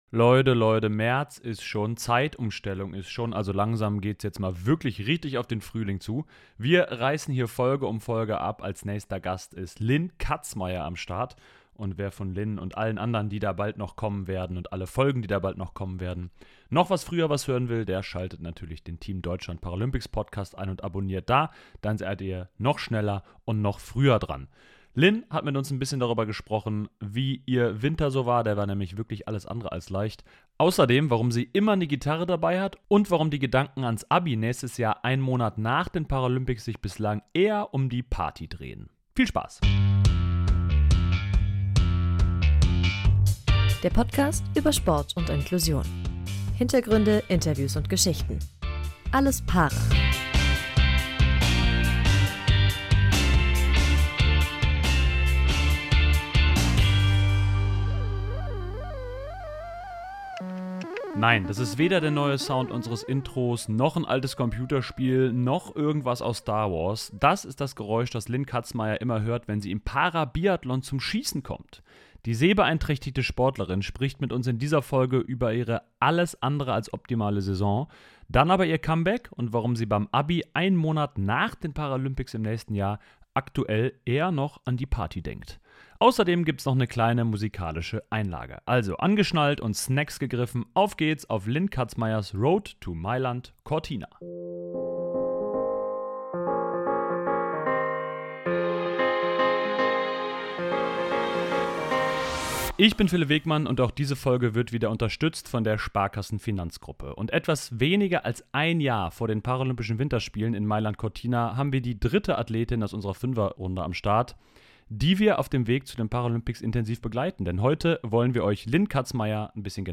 Kurz nach ihren zweiten Paralympics schreibt Linn Kazmaier ihr Abitur. Im Team Deutschland Paralympics Podcast erzählt sie, warum sie dabei aktuell eher an die Abi-Party denkt als ans Lernen, warum die Paralympics sich für sie eher wie ein gewöhnlicher Weltcup anfühlen werden und sie gibt eine musikalische Einlage zum Besten.